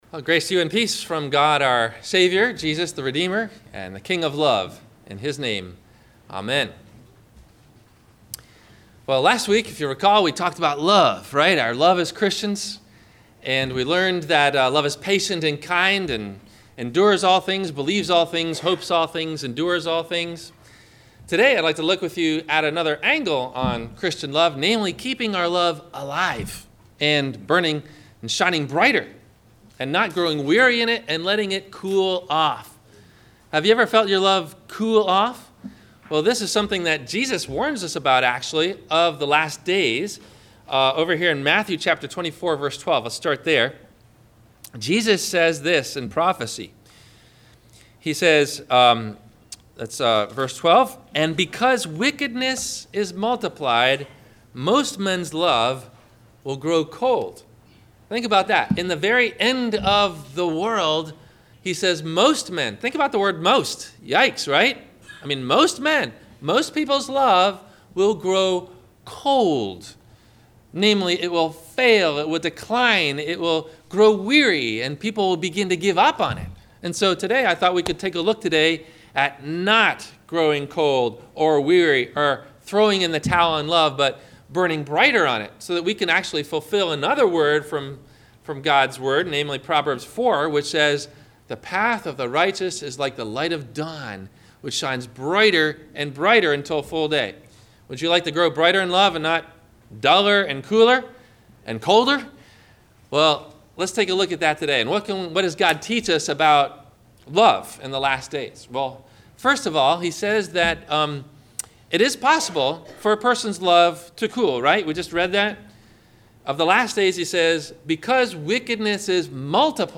The Steadfast Love of God's People - Sermon - June 19 2016 - Christ Lutheran Cape Canaveral